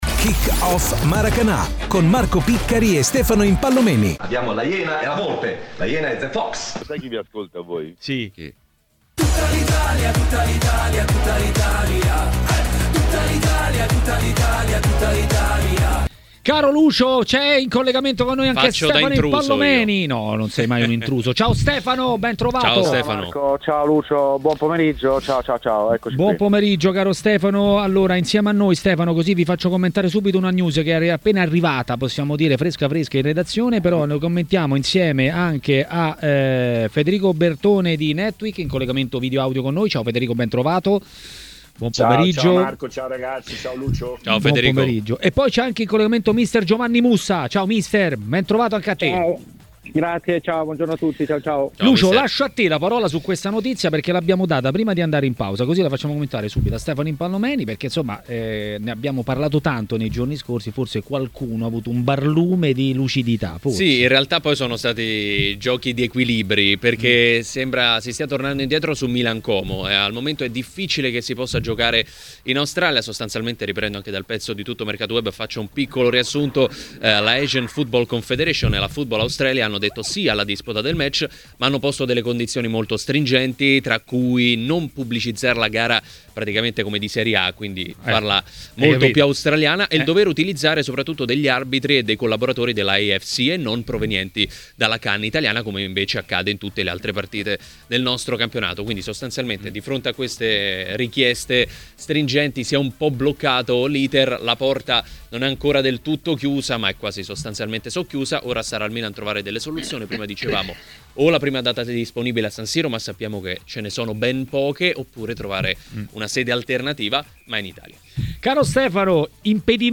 ha parlato dei temi del campionato a TMW Radio, durante Maracanà.